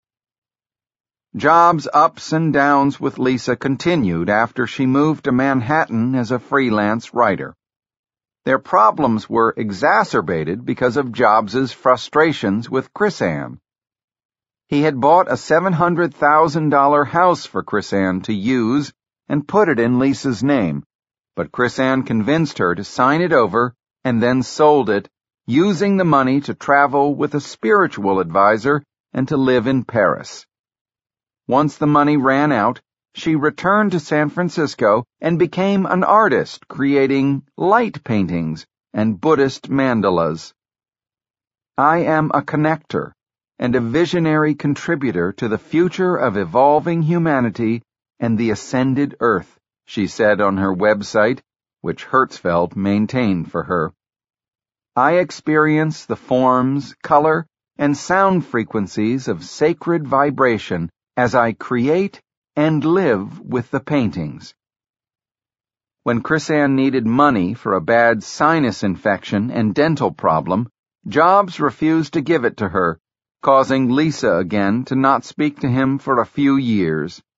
在线英语听力室乔布斯传 第322期:丽萨住了进来(4)的听力文件下载,《乔布斯传》双语有声读物栏目，通过英语音频MP3和中英双语字幕，来帮助英语学习者提高英语听说能力。
本栏目纯正的英语发音，以及完整的传记内容，详细描述了乔布斯的一生，是学习英语的必备材料。